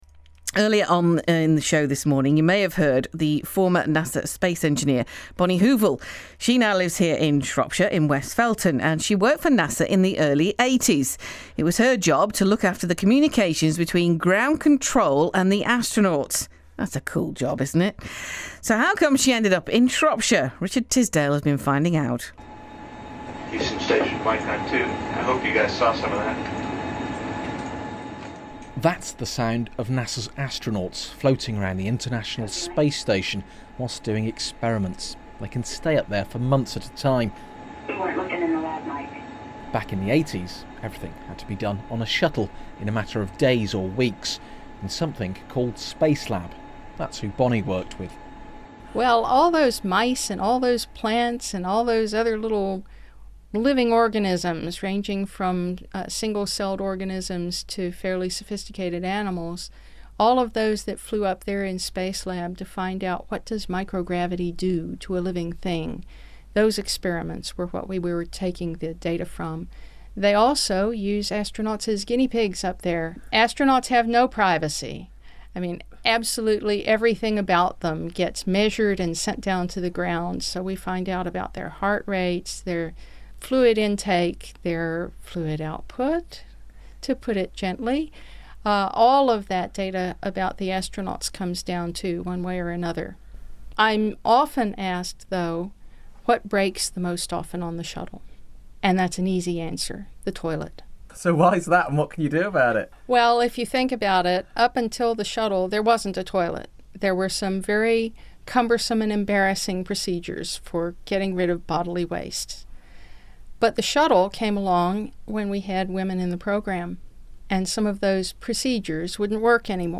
The day before the meeting, during prime drive-time slots the station aired four clips from the interview ranging up to about 4 minutes long.